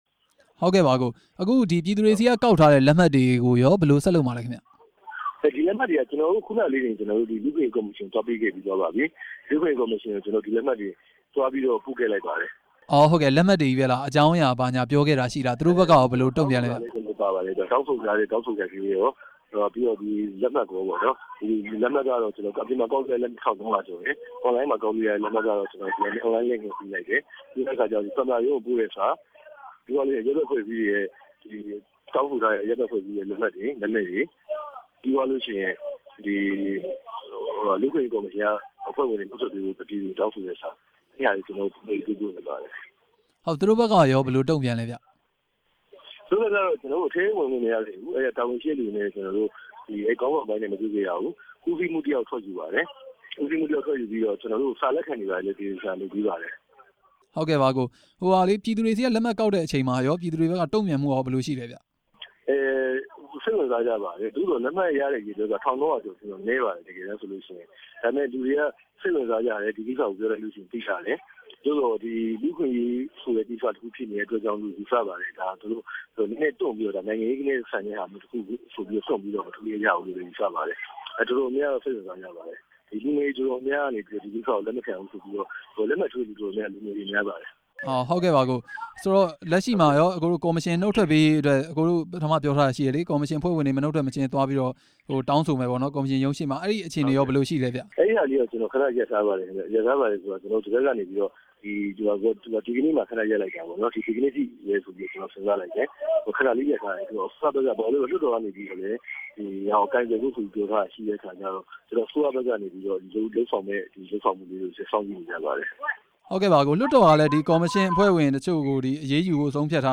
လူ့အခွင့်အရေးကော်မရှင်ကို အရေးယူပေးဖို့ တောင်းဆိုတဲ့အကြောင်း မေးမြန်းချက်